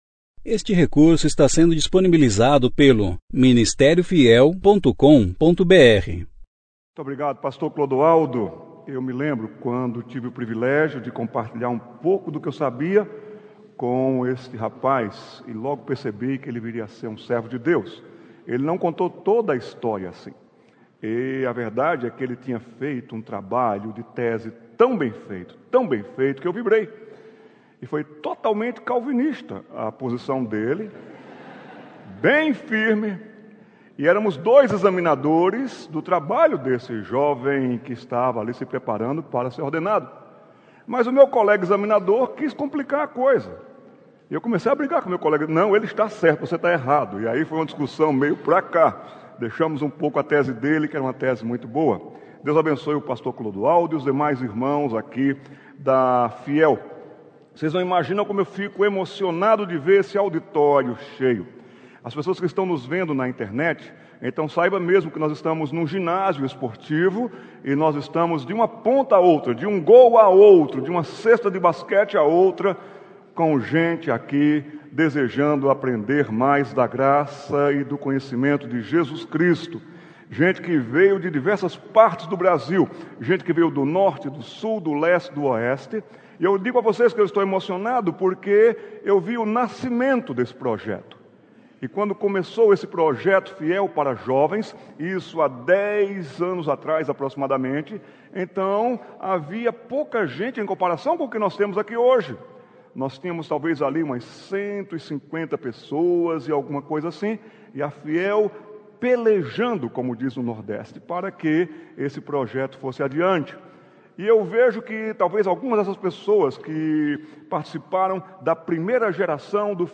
Conferência: 12ª Conferência Fiel para Jovens Tema: Escravo: Rendendo-se ao Senhorio de Cristo Ano: